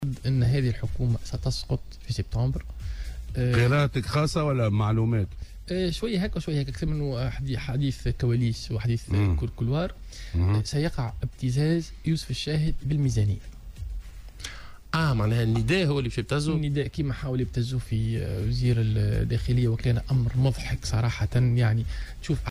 قال نائب مجلس الشعب، ياسين العياري في مداخلة له اليوم في برنامج "صباح الورد" على "الجوهرة أف أم" إن حكومة يوسف الشاهد ستسقط خلال شهر سبتمبر القادم.